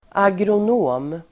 Uttal: [agron'å:m]